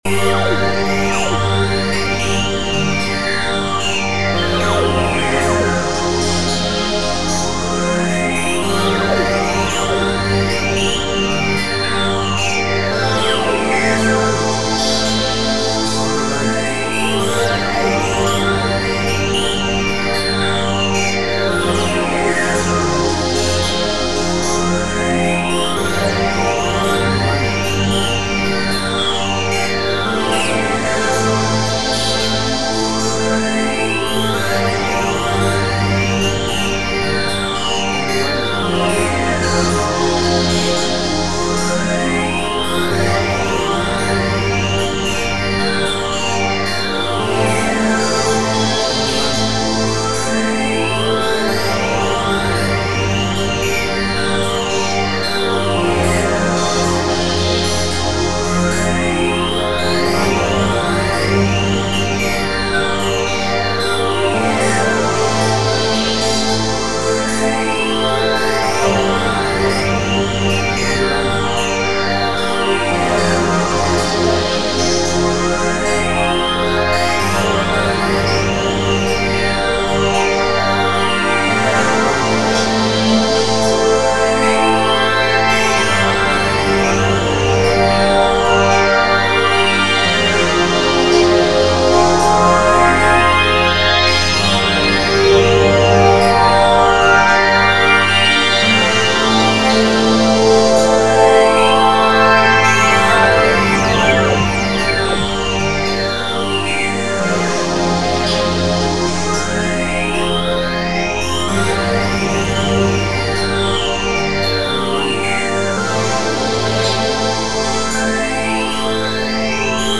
Tempo (BPM): 110